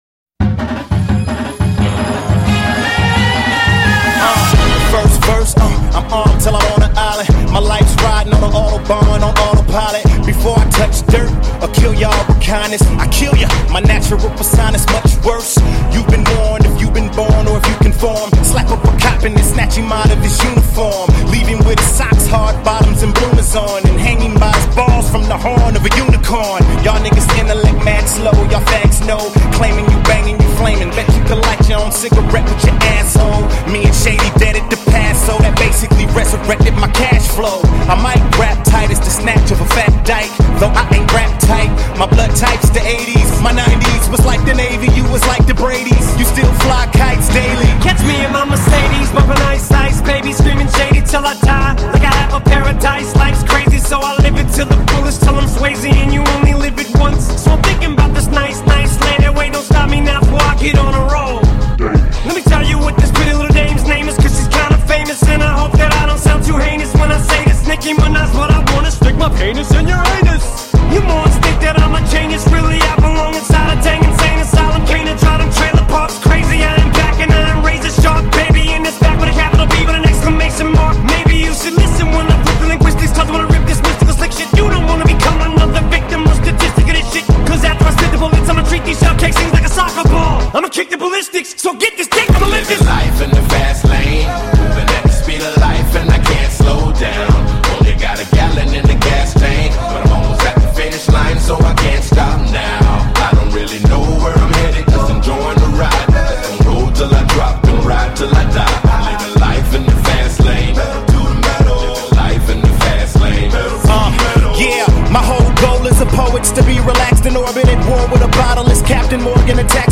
یه آهنگ فوق سریع